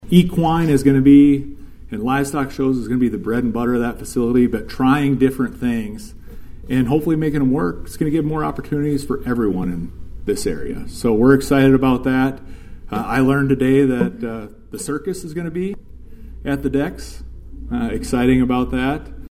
At the Lincoln Day Dinner held at the Huron Events Center last Thursday, South Dakota secretary of the Department of Agriculture and Natural Resources, Hunter Roberts was the keynote speaker.